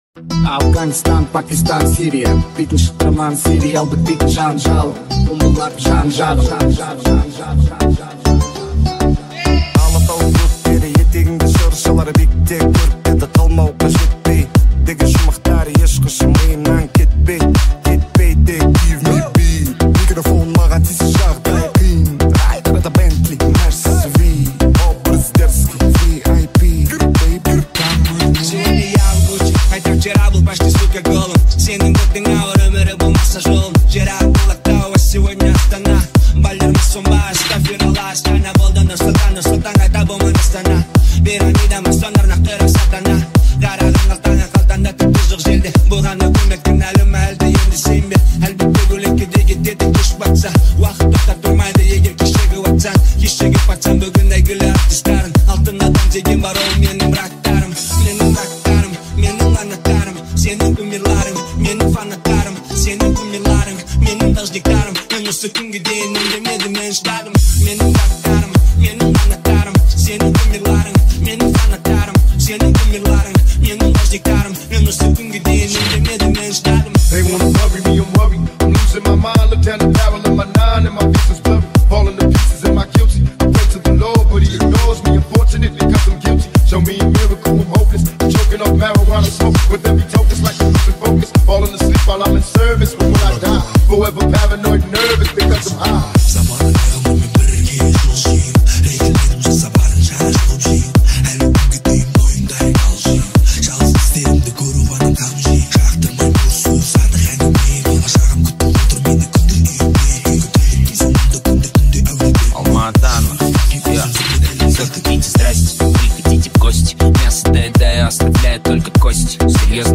мэшап ремикс песня афганистан пакистан музыка